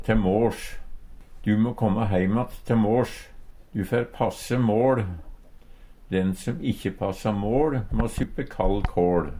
te måsj - Numedalsmål (en-US)